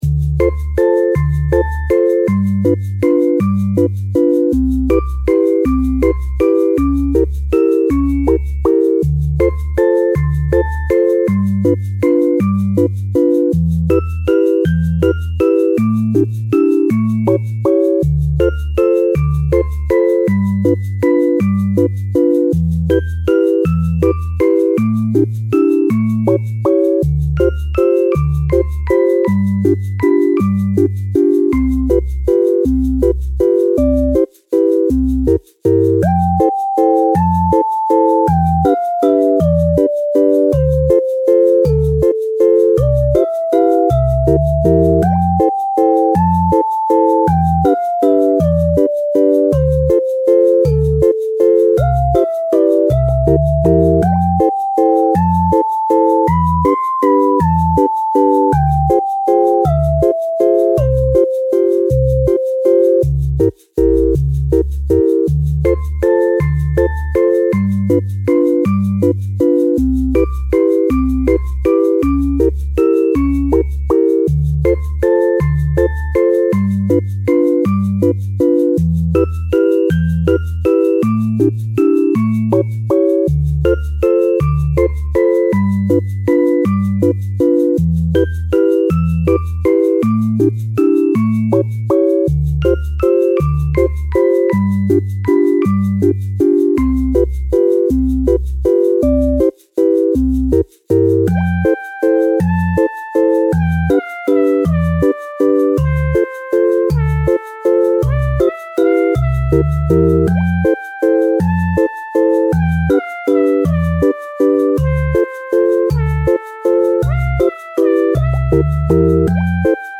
ポップ
かわいい ほのぼの ぽこぽこ まったり落ち着く チル ピコピコ 日常
優しくてかわいい雰囲気の楽曲です🐇🐈🍞 作業用,配信用の他にも料理Cooking用にもどうぞ
Loopとは余白、アウトロなし、そのまま繰り返し繋げれられるバージョンです